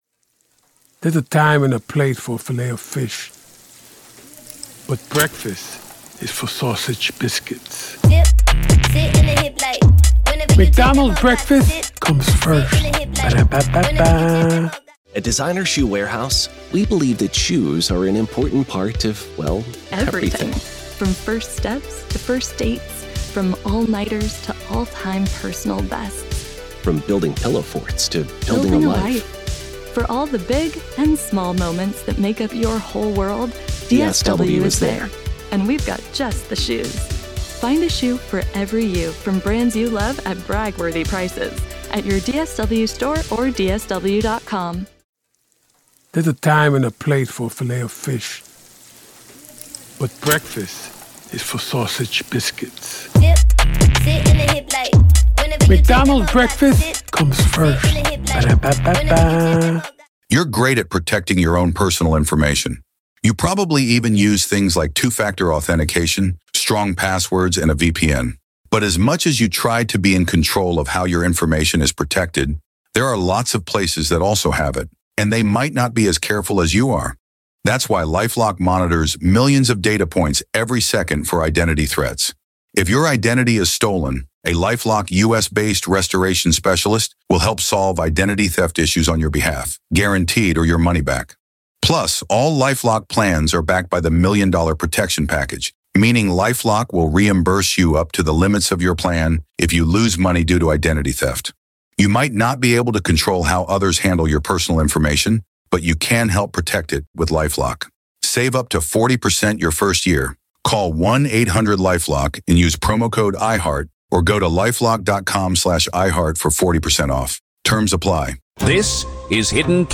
True Crime Today | Daily True Crime News & Interviews / Is Kohberger’s Team Preparing to Blame the Surviving Roommates?